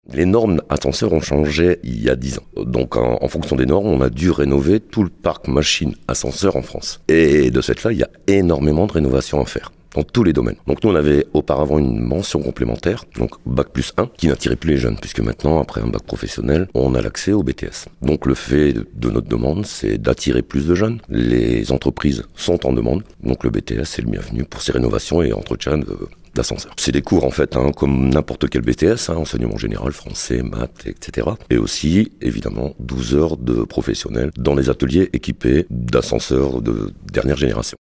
Interview de
Enseignant